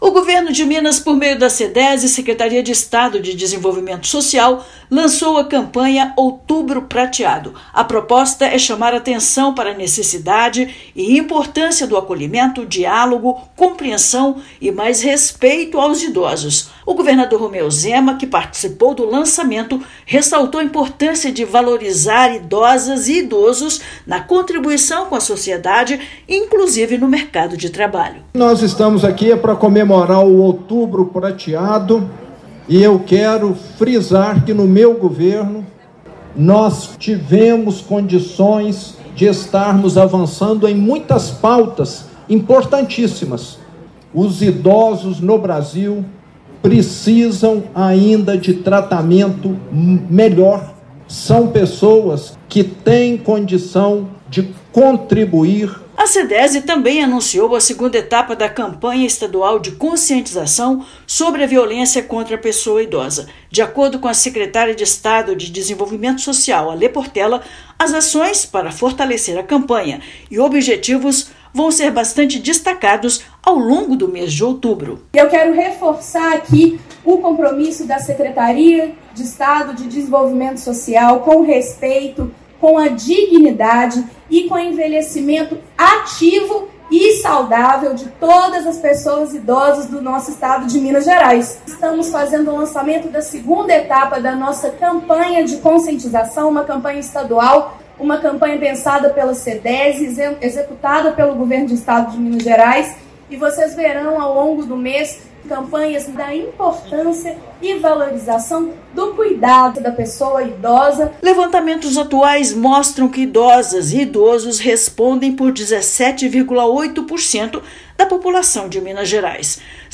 Encontro que também celebra o Dia Internacional da Pessoa Idosa chama a atenção para cuidados e políticas públicas voltadas à população 60+. Ouça matéria de rádio.